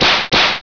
Whip Whoosh 4